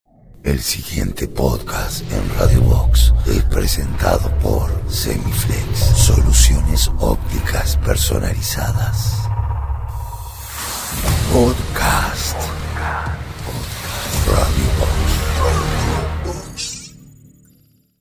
CUÑAS EN PODCAST: APERTURA / CIERRE
INTRO-PODCAST-PRESENTA.mp3